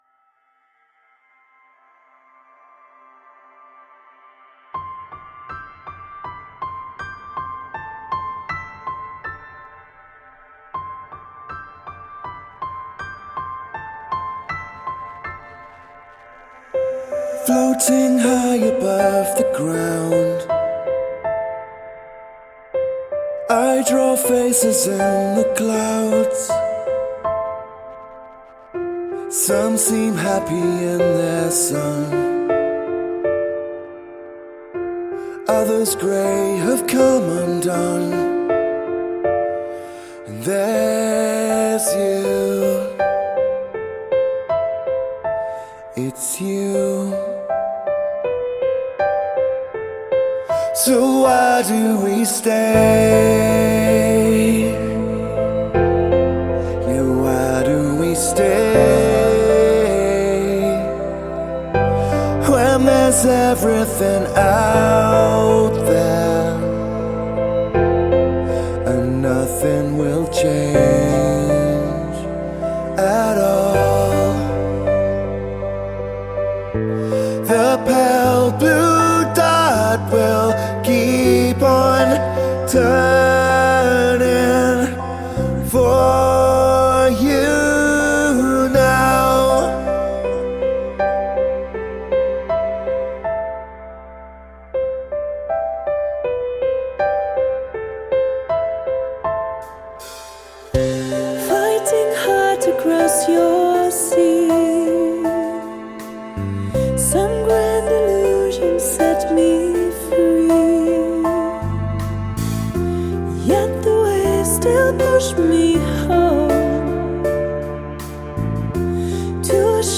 prog album